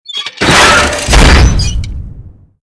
CHQ_VP_big_jump_stomp.mp3